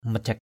/mə-ʥak/ majak mjK [Bkt.]